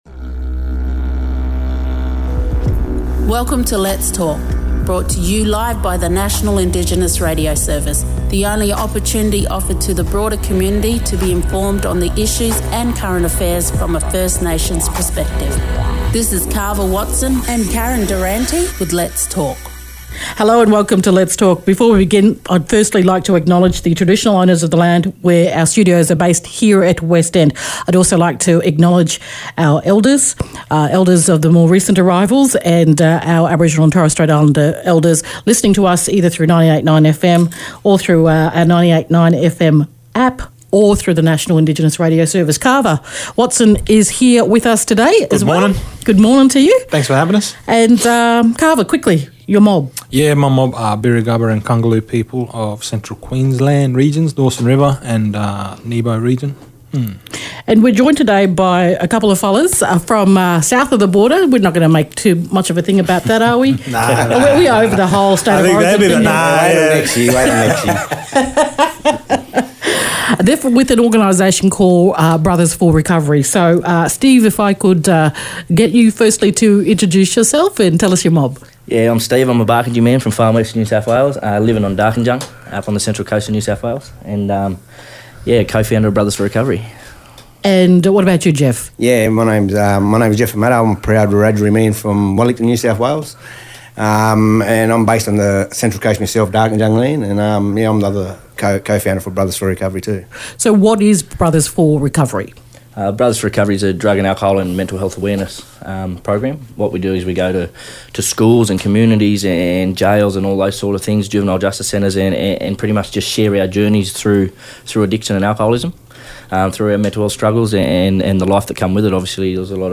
popped into 98.9fm for a yarn